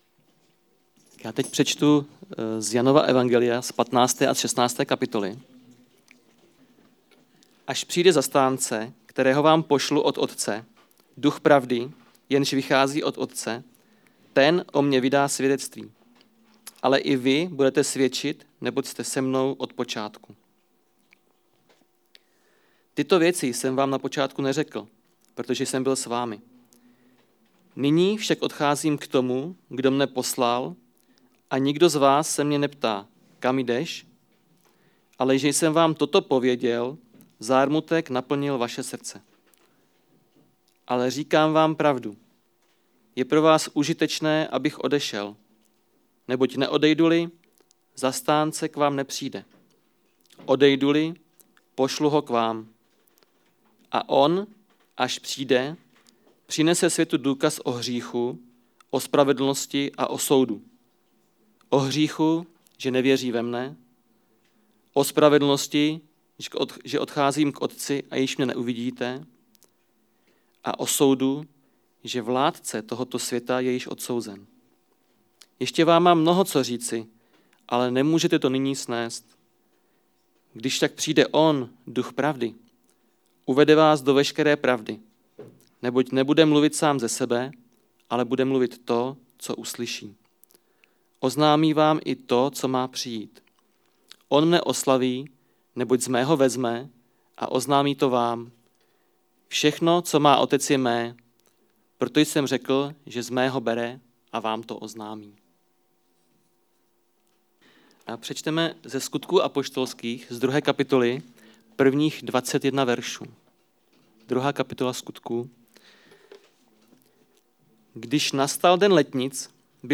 Kázání – 10. stránka – ECM Jihlava